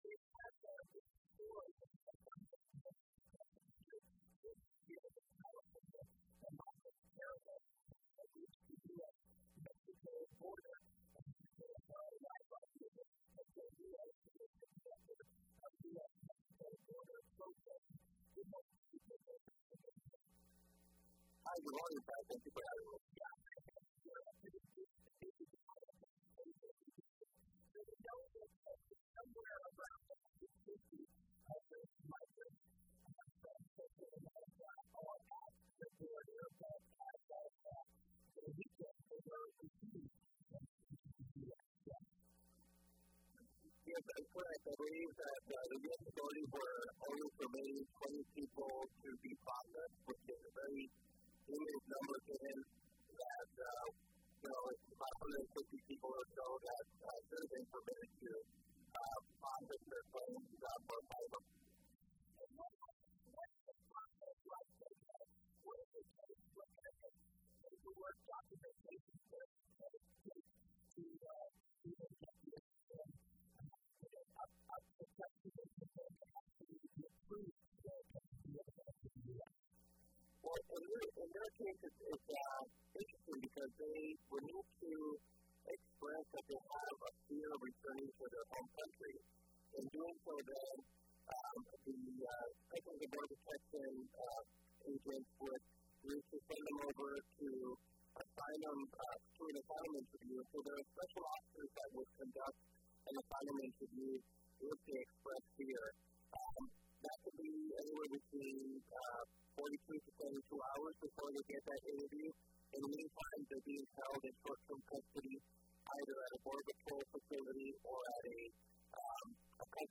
Interview: Why Have the Members of the Migrant Caravan Been Denied Entry into the U.S.?